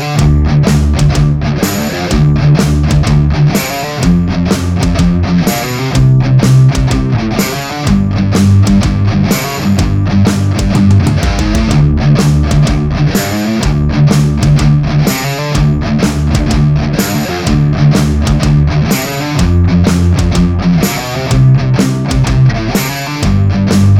no Backing Vocals Indie / Alternative 5:18 Buy £1.50